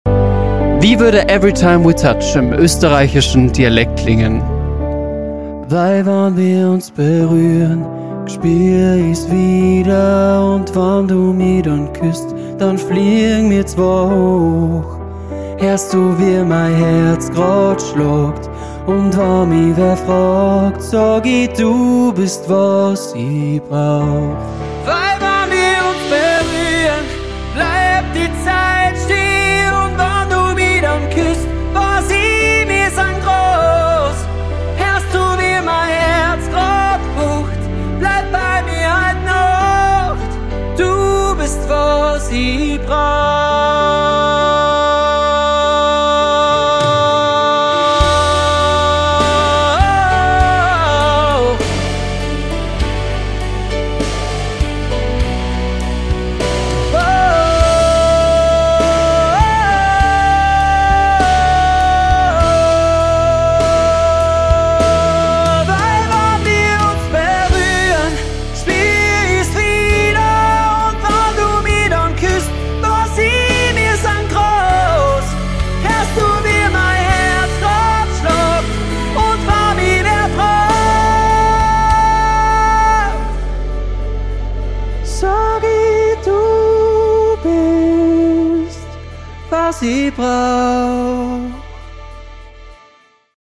im österreichischen Dialekt
Austro-Pop